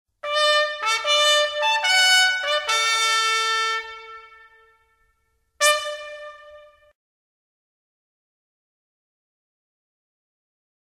Toques e Fanfarra das Tropas Pára-quedistas